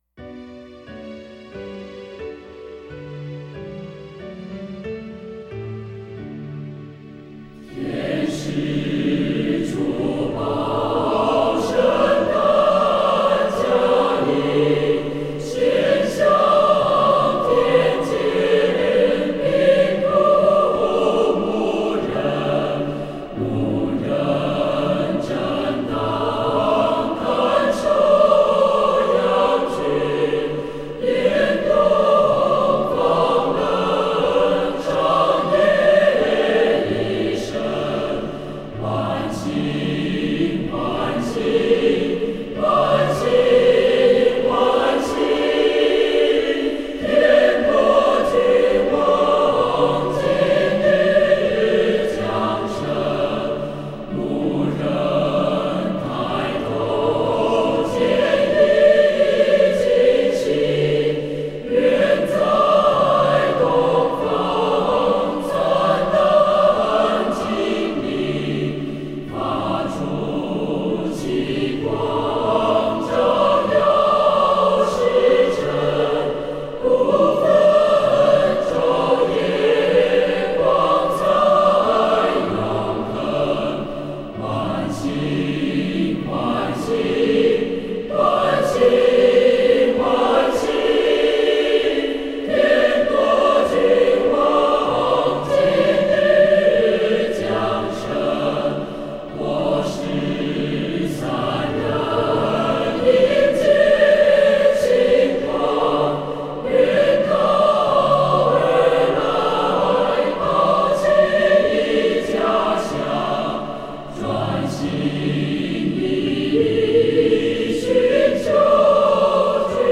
词曲：传统英国合唱曲